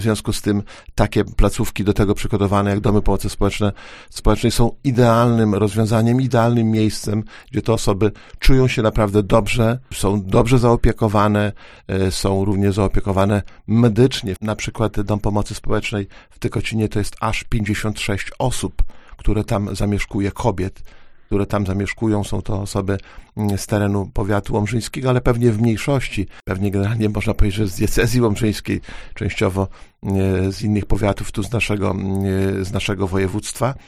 Zapotrzebowanie na miejsca w Domach Pomocy Społecznej nie maleje, a rośnie – mówił w audycji Gość Dnia starosta łomżyński, Lech Szabłowski.